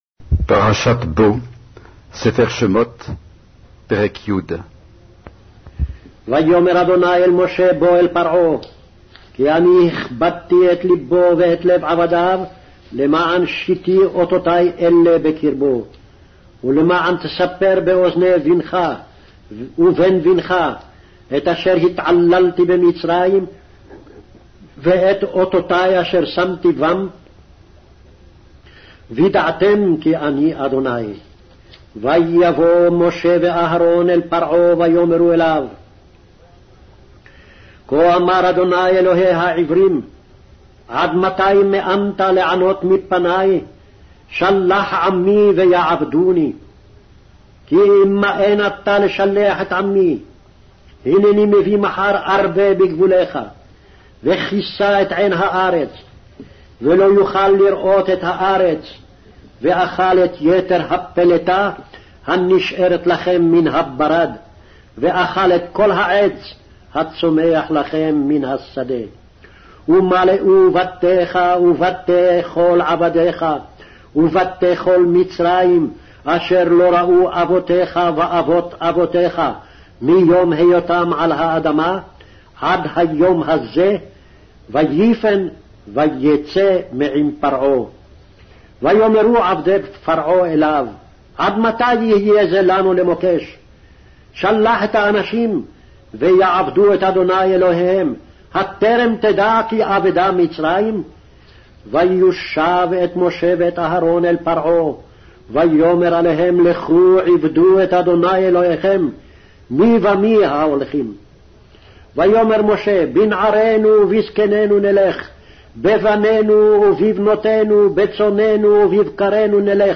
Hebrew Audio Bible - Exodus 21 in Akjv bible version